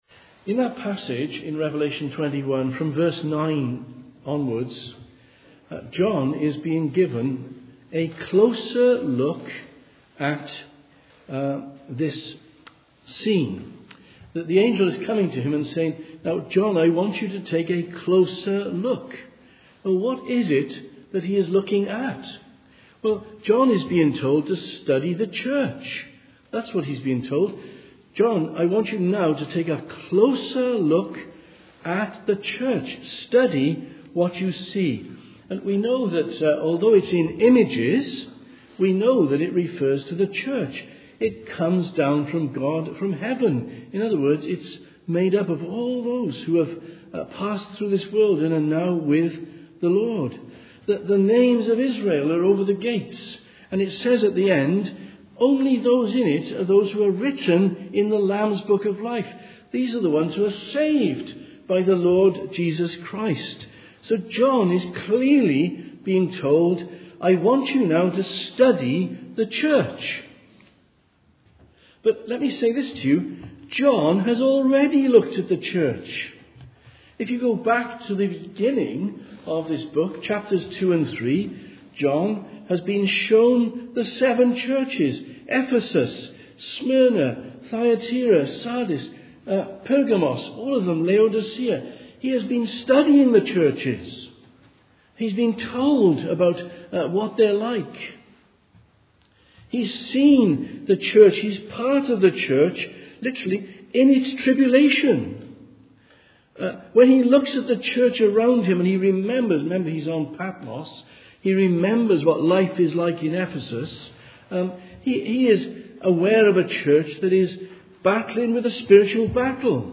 Sermons - Immanuel Presbyterian Church